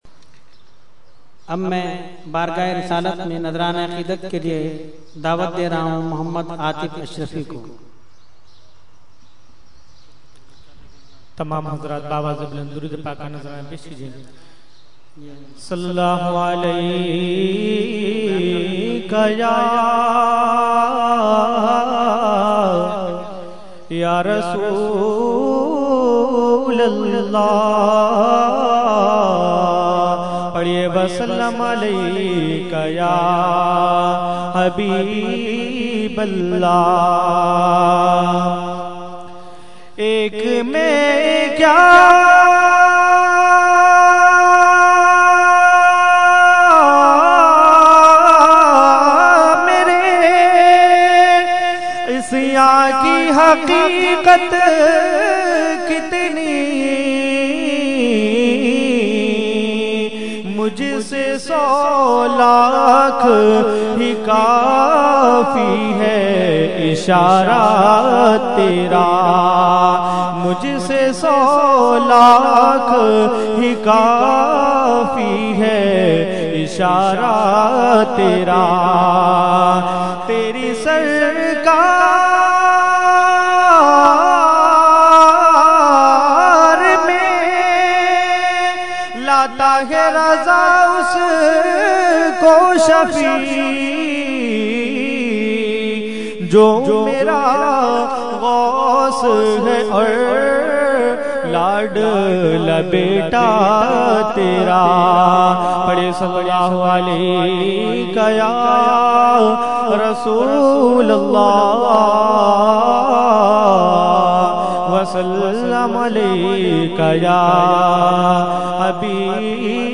Category : Naat | Language : UrduEvent : Urs Qutbe Rabbani 2012